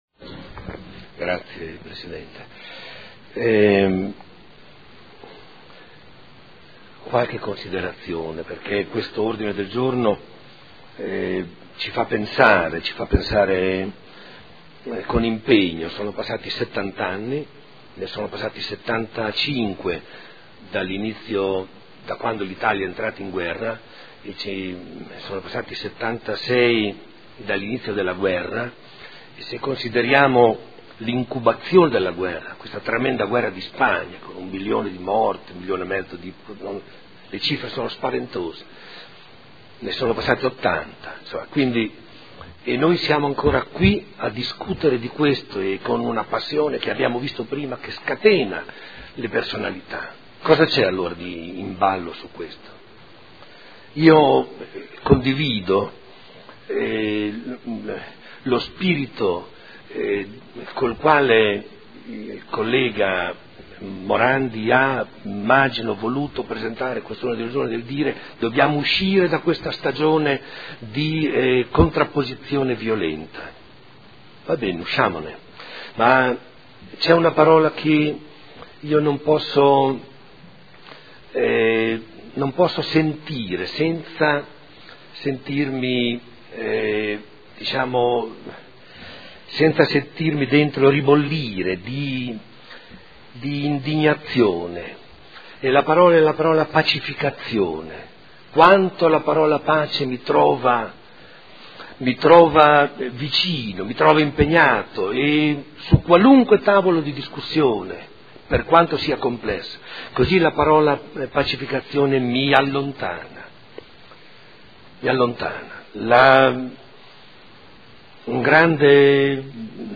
Domenico Campana — Sito Audio Consiglio Comunale